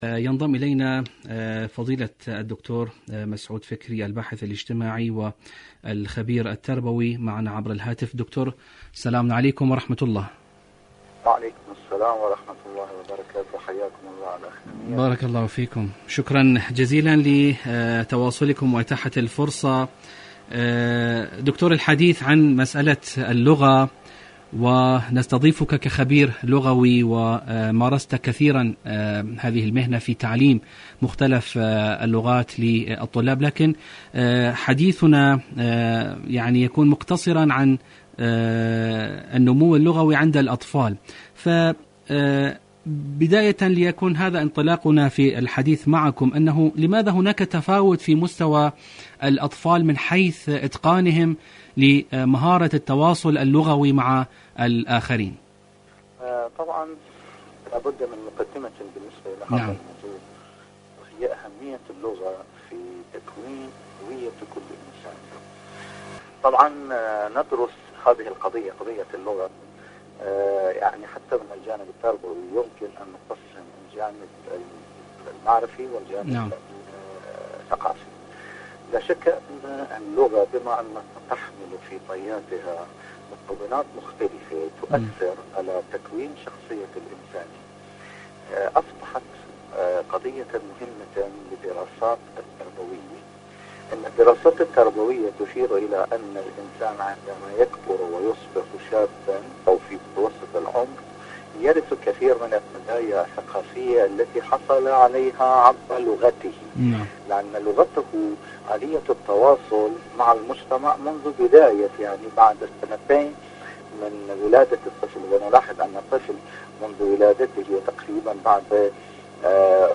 مقابلات برامج إذاعة طهران العربية برنامج معكم على الهواء الأطفال اللغة النمو النمو اللغوي النمو اللغوي عند الأطفال مقابلات إذاعية شاركوا هذا الخبر مع أصدقائكم ذات صلة دور العلاج الطبيعي بعد العمليات الجراحية..